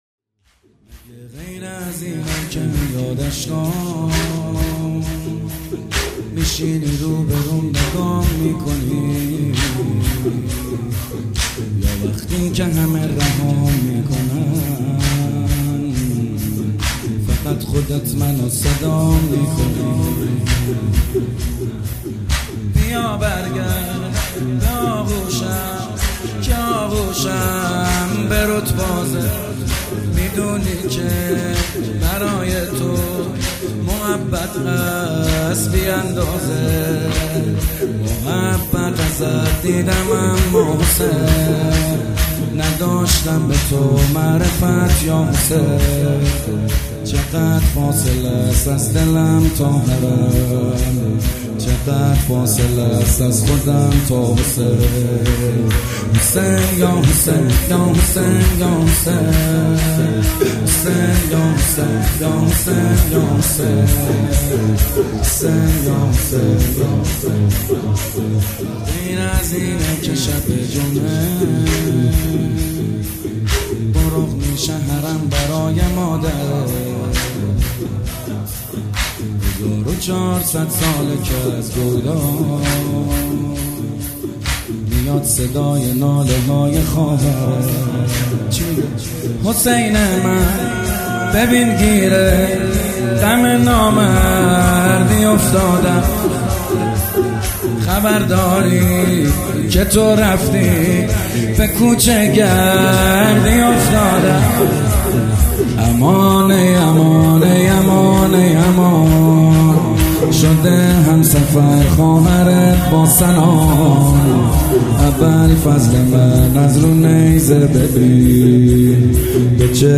شور احساسی